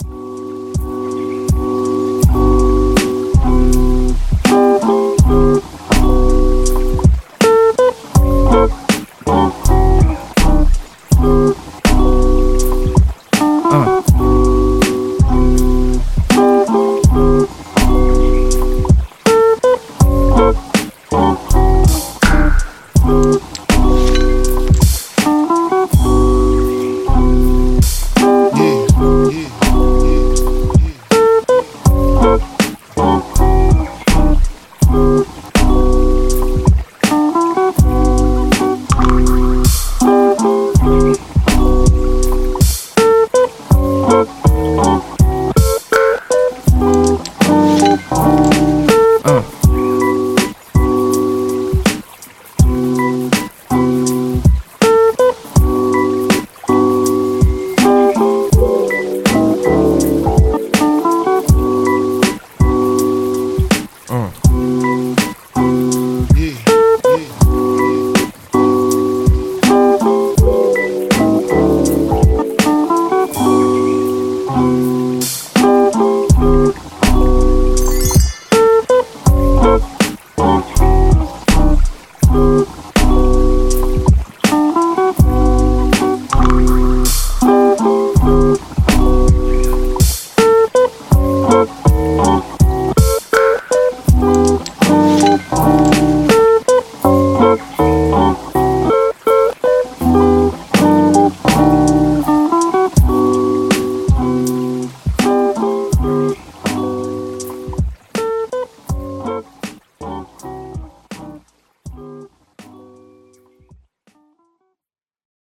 Gospel
poet spoken words and reggae gospel artist.